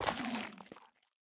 Minecraft Version Minecraft Version snapshot Latest Release | Latest Snapshot snapshot / assets / minecraft / sounds / block / sculk / step4.ogg Compare With Compare With Latest Release | Latest Snapshot
step4.ogg